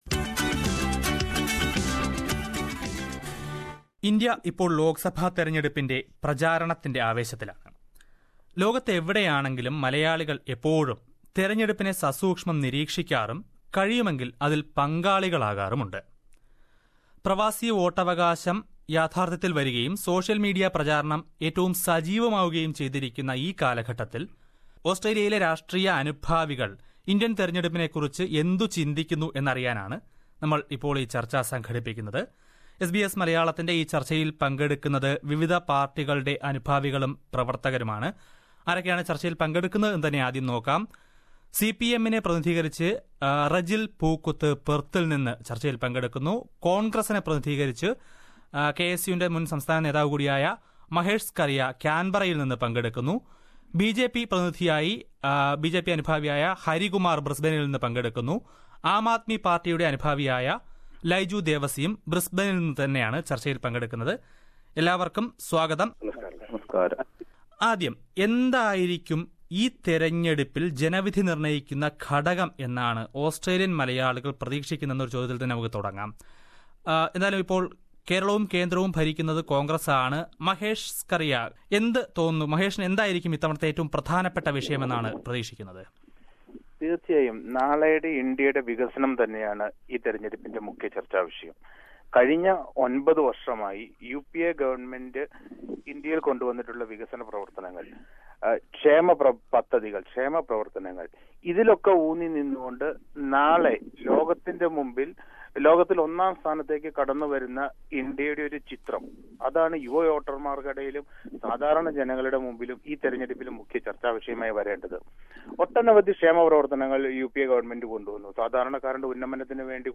What does the Malayalee community in Australia think about the Indian election? What are the factors which decide the vote? A panel discussion of Malayalees in Australia